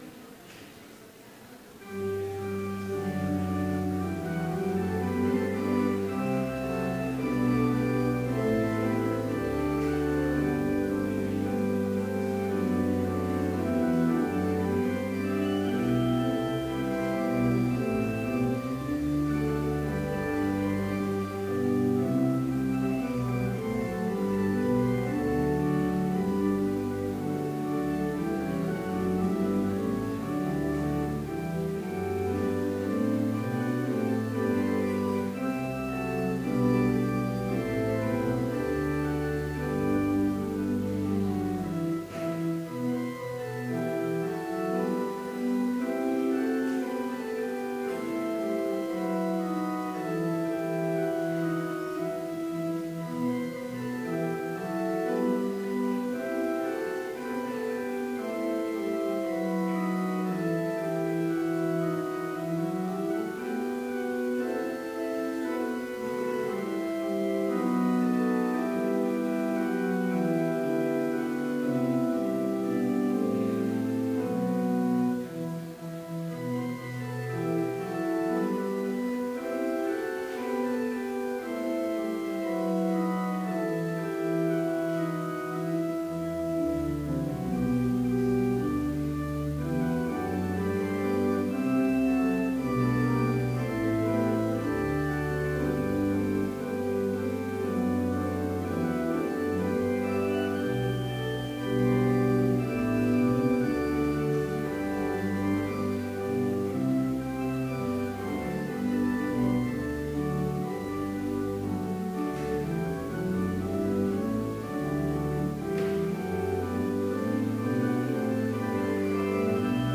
Vespers service in Bethany Lutheran College's chapel
Complete service audio for Evening Vespers - April 13, 2016
(led by the choir)
(All sing the triple Alleluia after each verse)